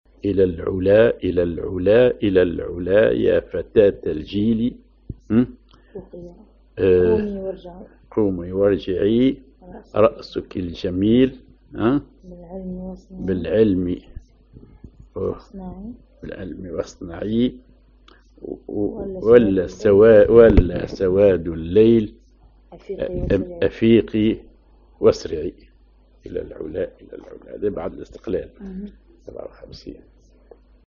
ar دو كبير
ar مارش